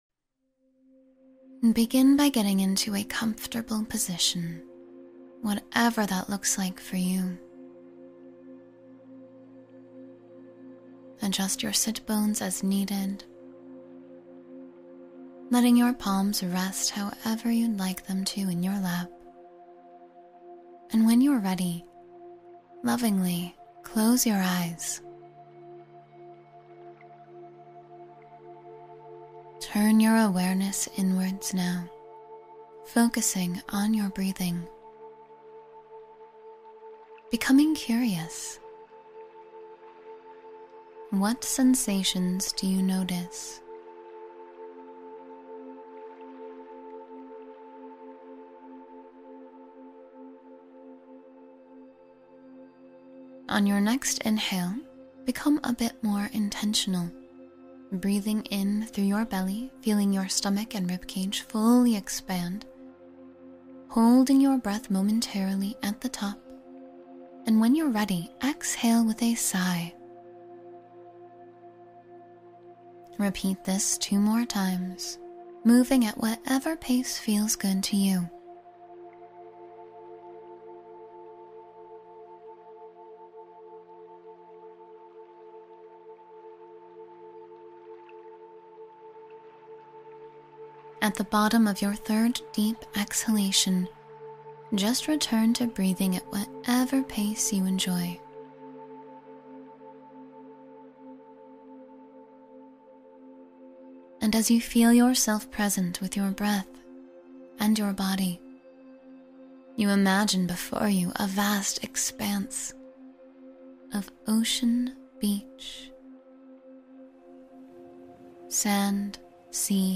Relax with a Beachside Guided Meditation — Feel the Calm of the Ocean